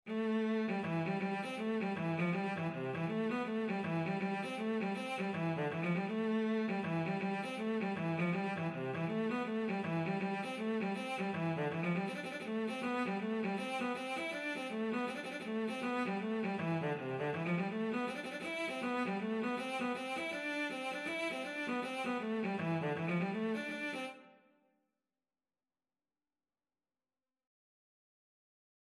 Cello version
C major (Sounding Pitch) (View more C major Music for Cello )
4/4 (View more 4/4 Music)
Cello  (View more Easy Cello Music)
Traditional (View more Traditional Cello Music)